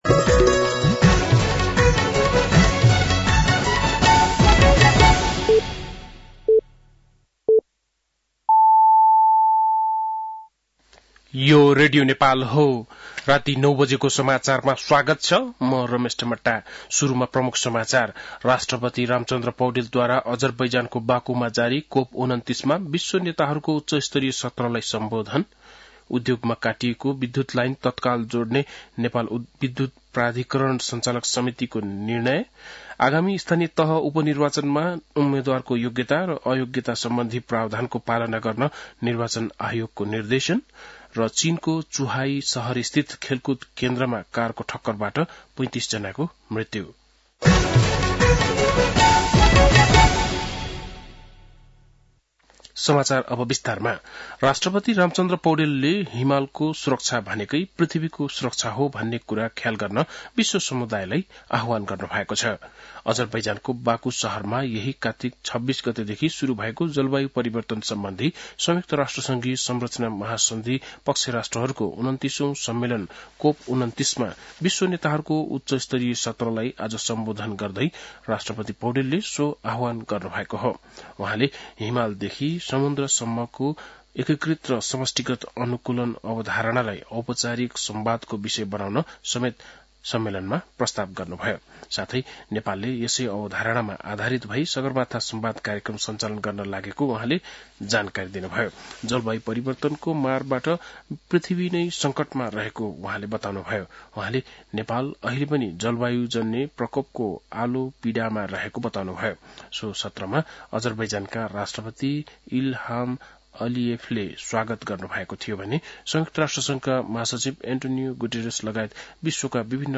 बेलुकी ९ बजेको नेपाली समाचार : २८ कार्तिक , २०८१